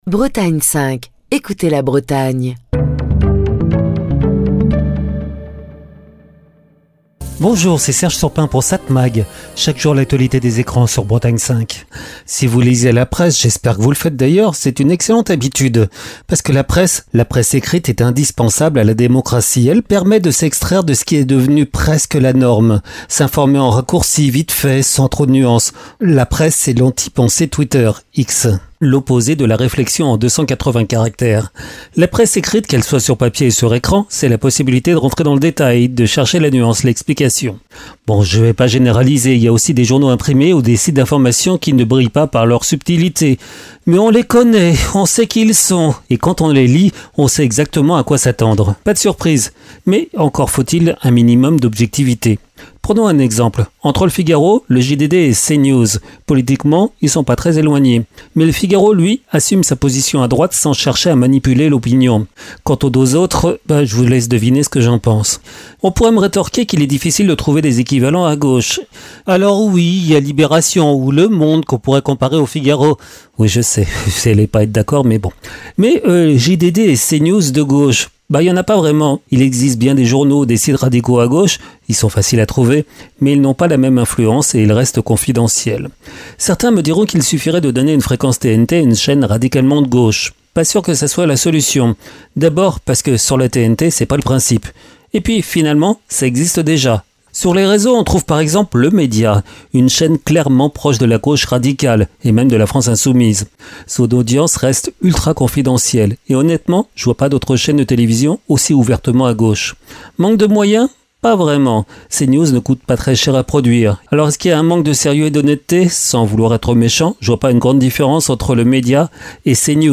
Chronique du 14 octobre 2025.